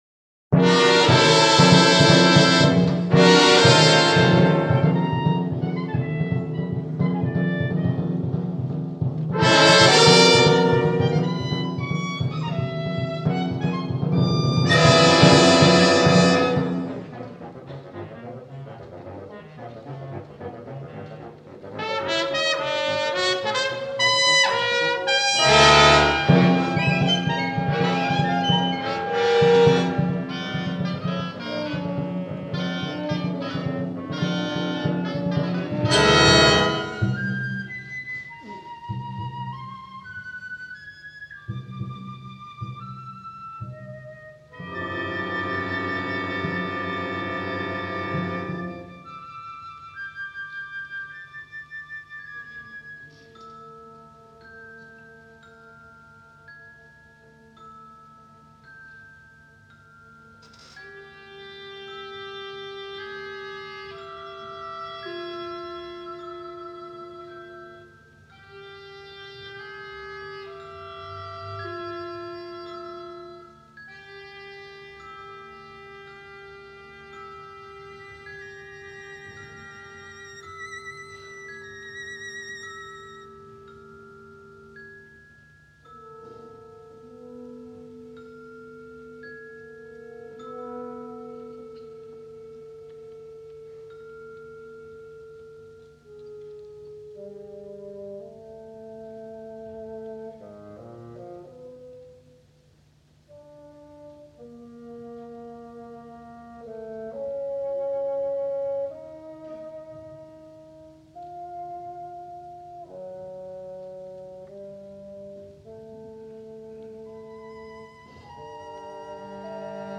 Cinco Epigramas para orquesta